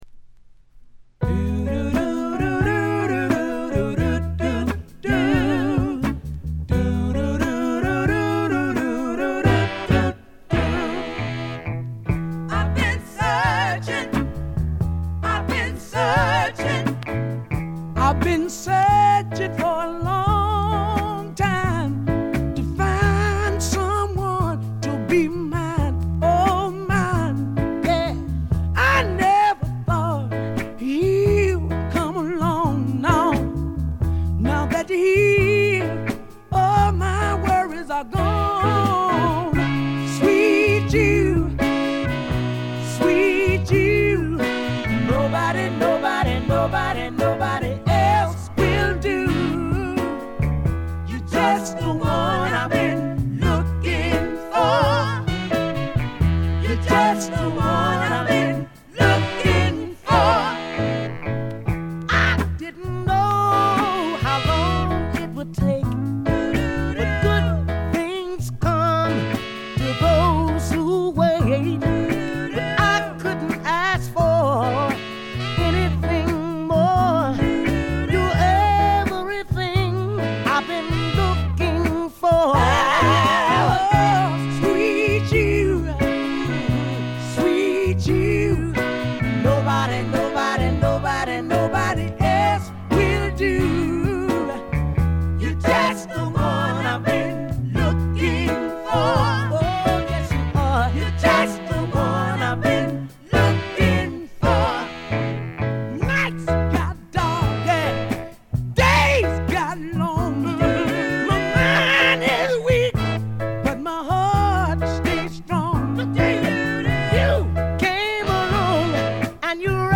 B2終盤でプツ音。
メンフィス産の男女4人組のソウル・ヴォーカル・グループ。
試聴曲は現品からの取り込み音源です。